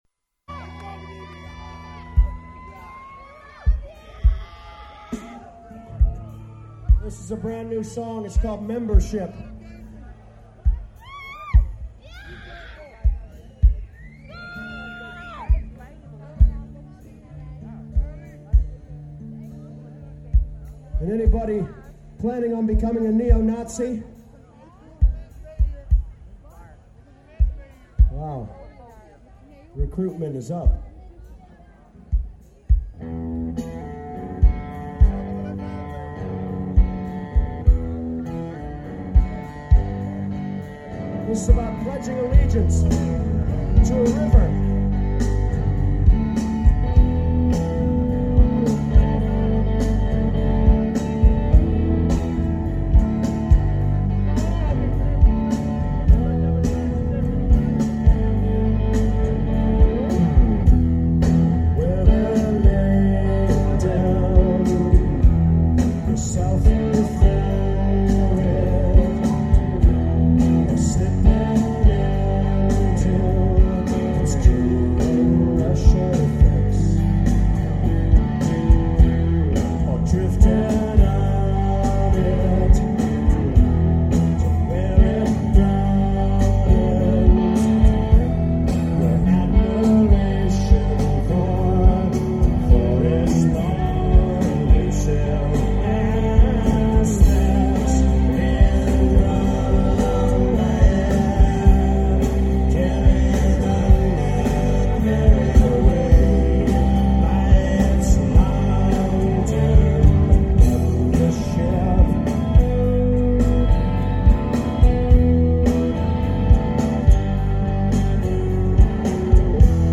Live In 1997